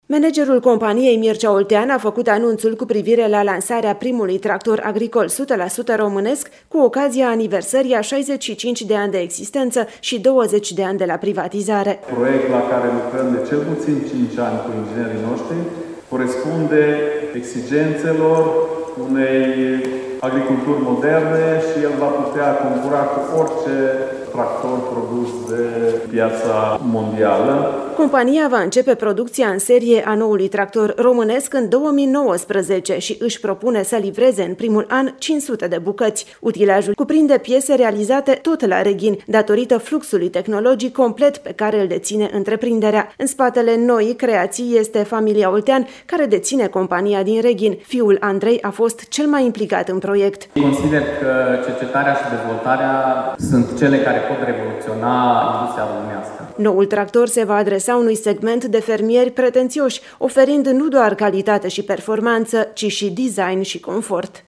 O companie din Reghin se pregăteşte să lanseze, în această toamnă, primul tractor agricol integral românesc, la 15 ani după ce producţia acestor utilaje a încetat. Tractorul le este destinat atât fermierilor români, cât şi celor din alte ţări europene. Corespondenta RR